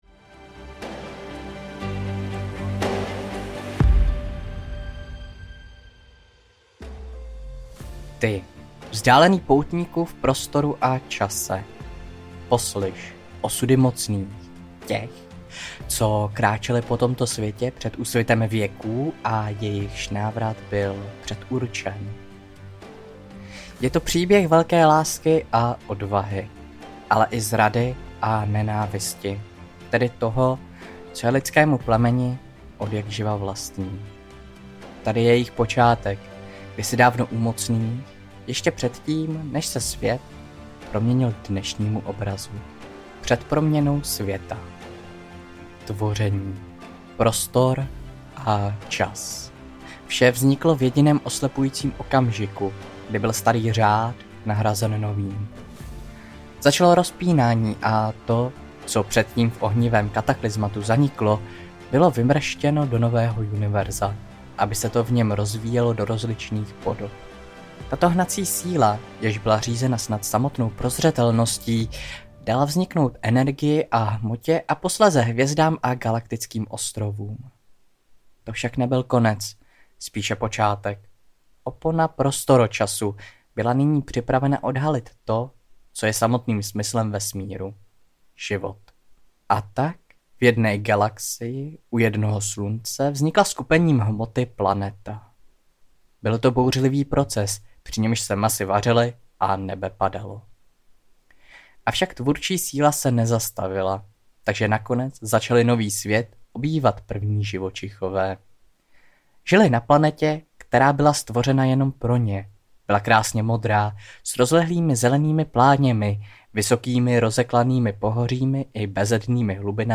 Mocní audiokniha
Ukázka z knihy